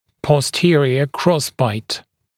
[pɔs’tɪərɪə ‘krɔsbaɪt][пос’тиэриэ ‘кросбайт]боковой перекрестный прикус